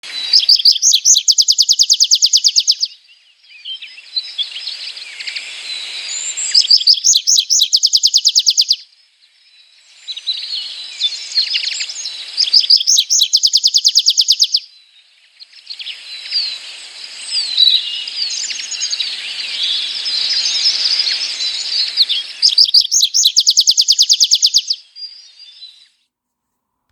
Fâsa de pădure (Anthus trivialis)
Este o pasăre mică, discretă, care trăiește la marginea pădurilor sau în luminișuri însorite. • Deși seamănă cu o vrabie, cântatul o dă de gol – urcă zburând și apoi „cade” ușor, fredonând un tril subțire.
Ascultă-i cântecul!
Fasa-de-padure.m4a